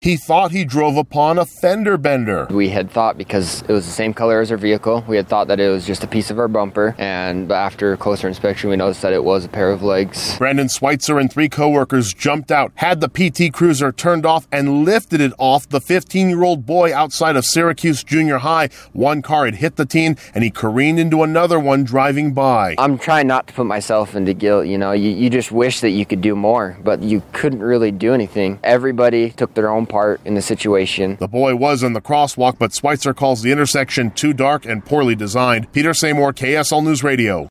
Construction worker recounts lifting car off of Syracuse teen